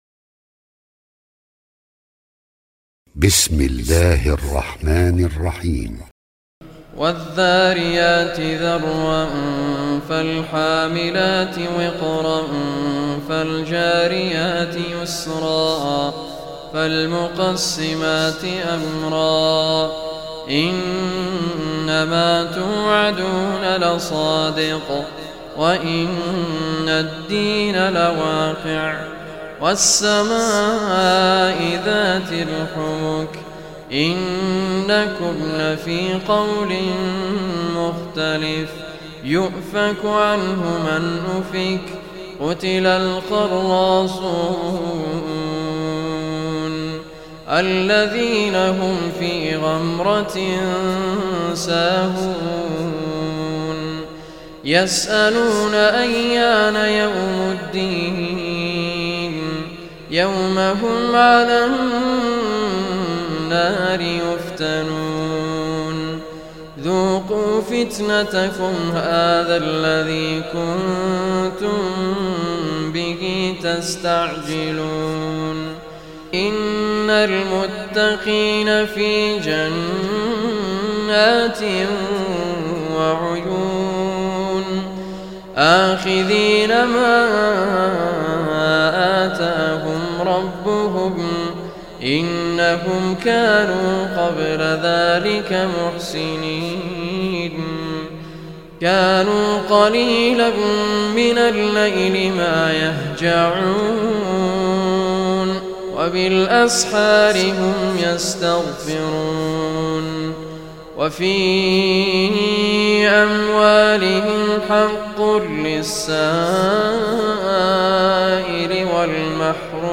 الاستماع للقرآن الكريم بصوت القارئ : رعد محمد الكردي